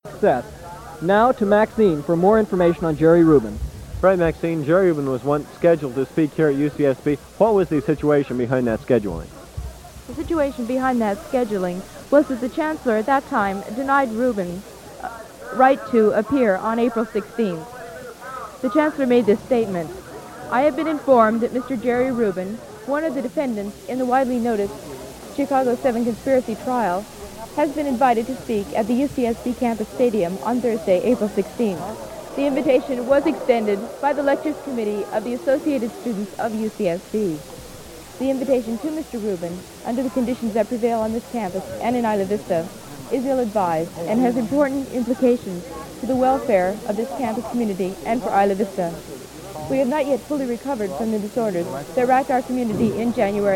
In the following recording, the KCSB radio station provides live coverage of the Jerry Rubin rally at UC Santa Barbara’s campus field. Although the speaking in the audio is clear, there is a lot of background noise in the recording. The sounds of a large crowd—cheering, chatting, bodies moving in close proximity—permeate throughout the recording. The report begins with various KCSB staffers giving background information on Jerry Rubin and the Chicago Seven trial.